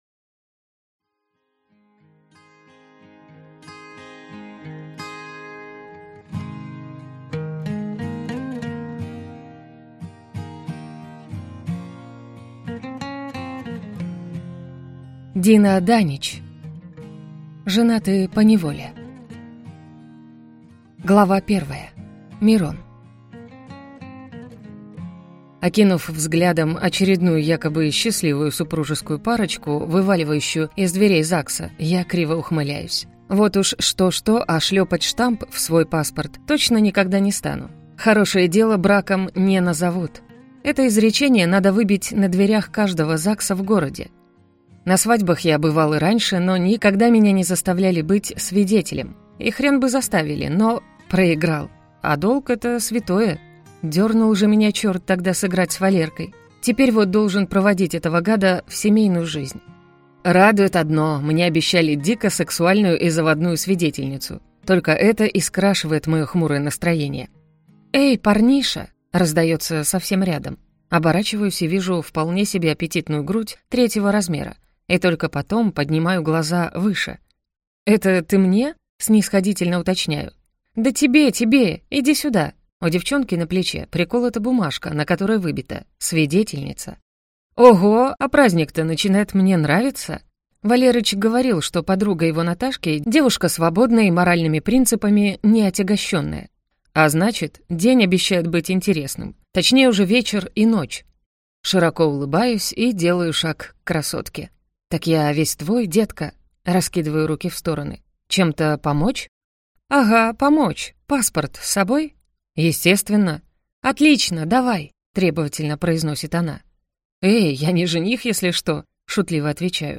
Аудиокнига Женаты поневоле | Библиотека аудиокниг